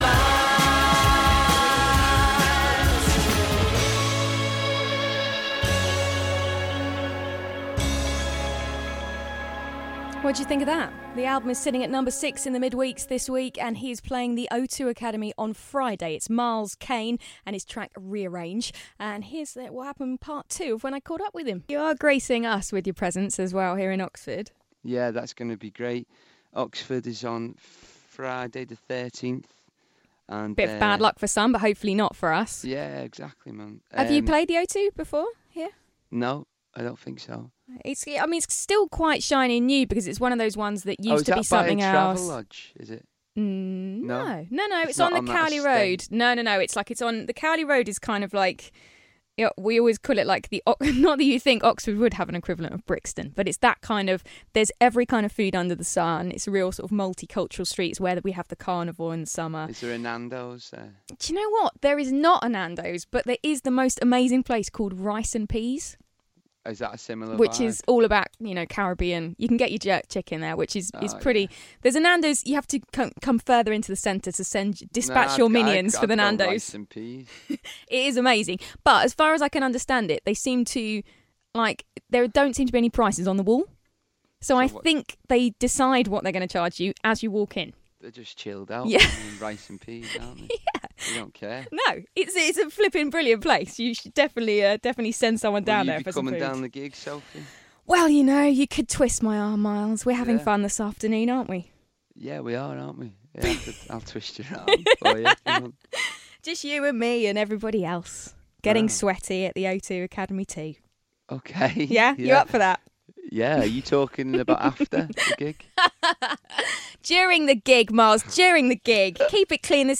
Miles Kane Interview Part 2